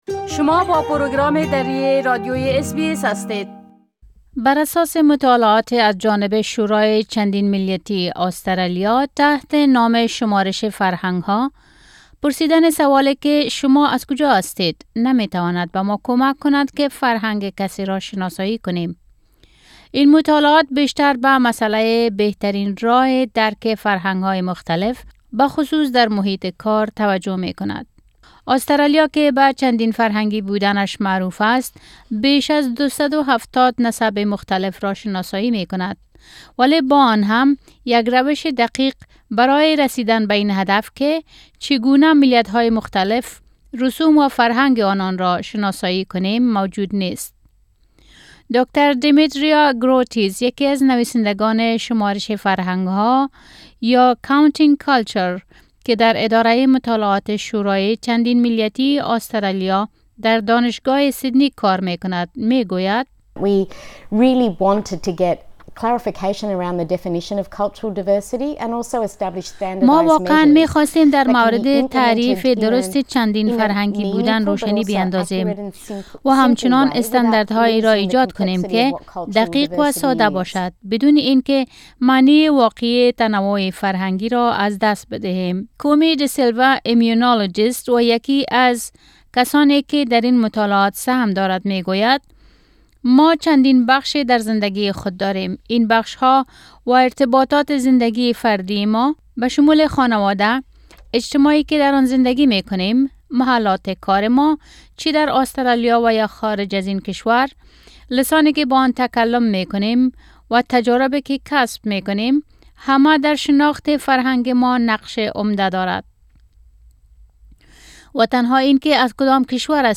تفصیلات را میتوانید در گزارش بشنوید.